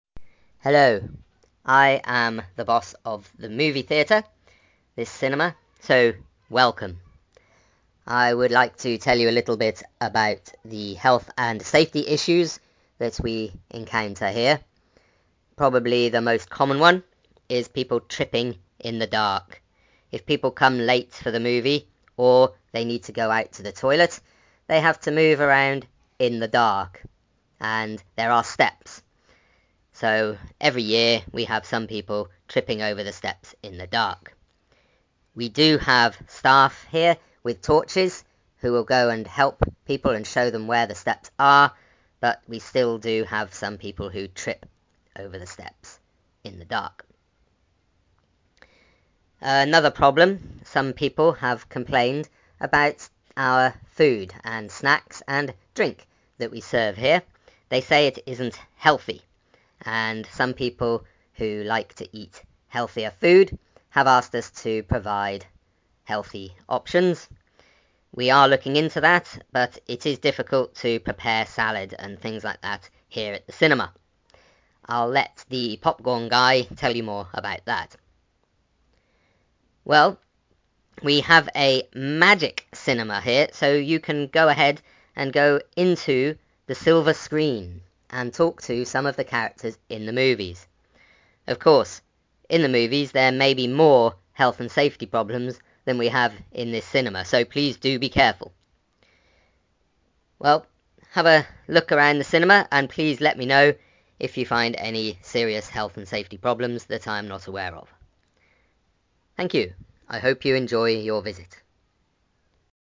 Listen to the manager of the cinema: